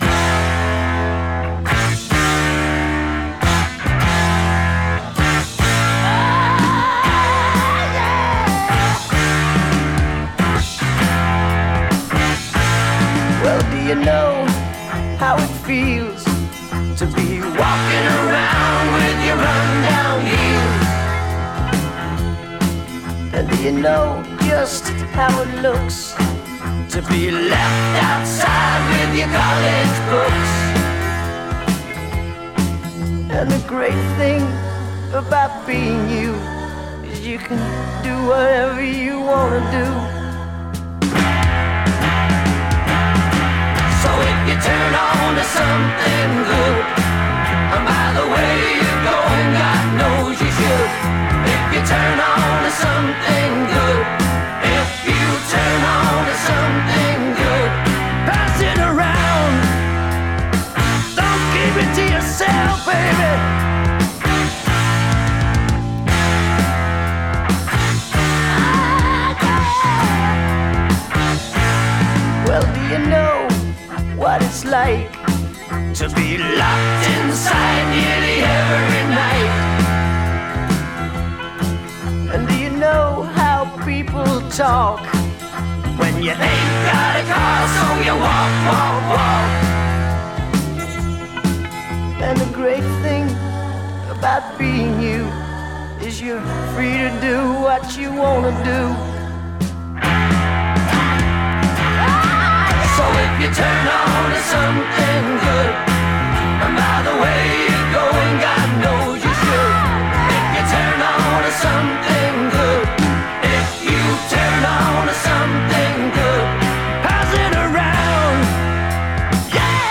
Genre: Pop Rock.